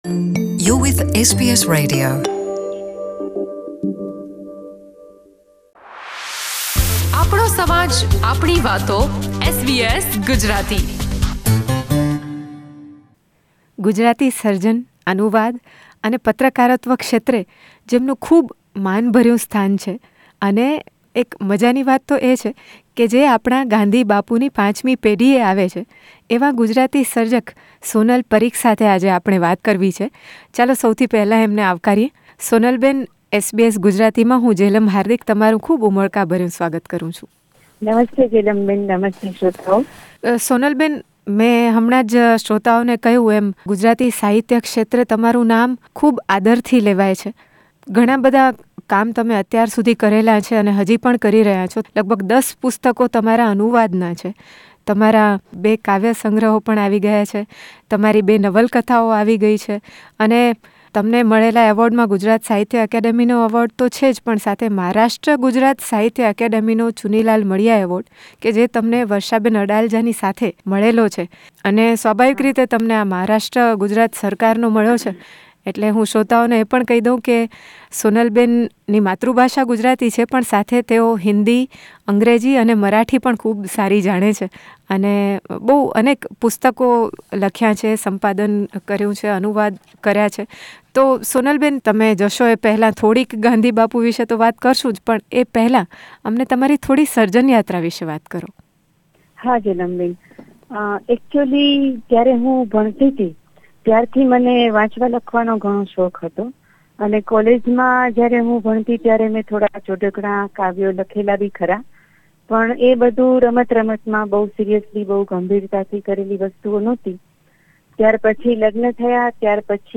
Gandhiji's letter to wife read aloud by great granddaughter